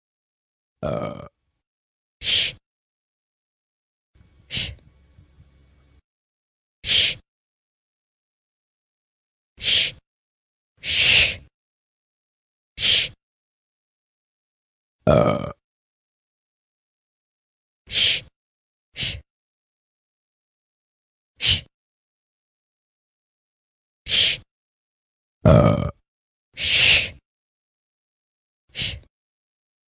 Secret Movie Theater Burp Cell Phone Ringtone
SecretMovieTheaterBurpRingtone.mp3